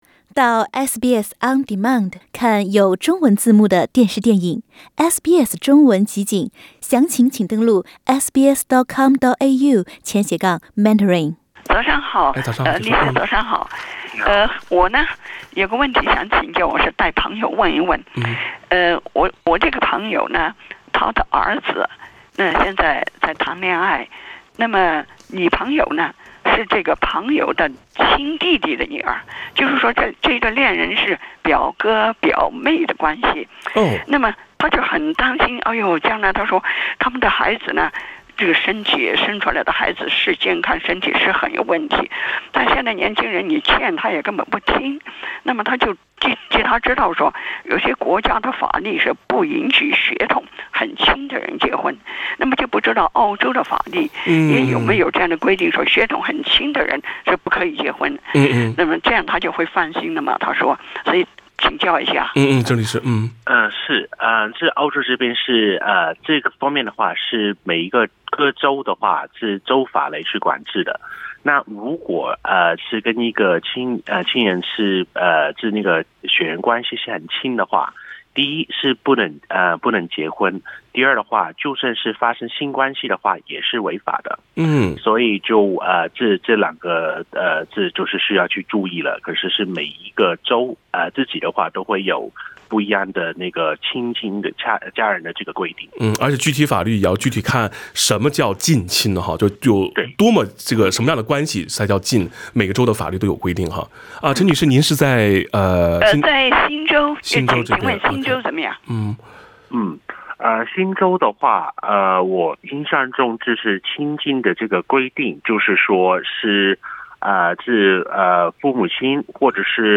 《现场说法》听众热线